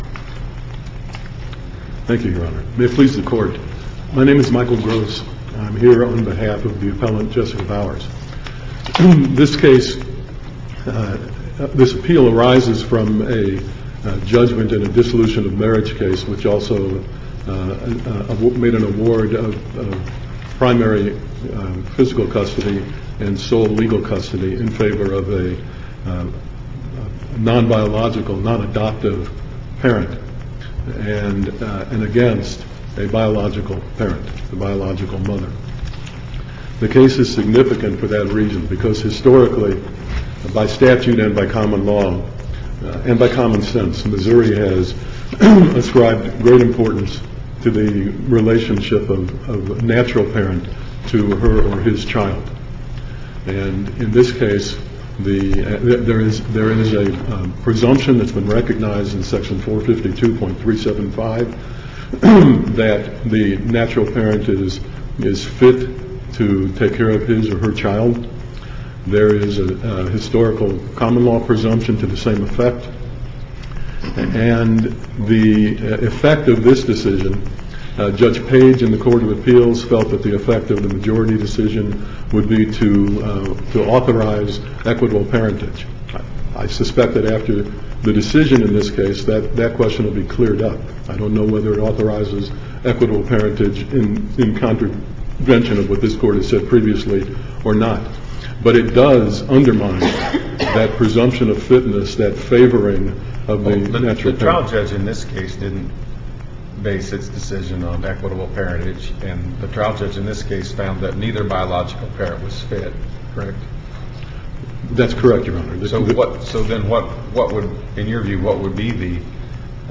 Whether labor and industrial relations commission must approve settlement Listen to the oral argument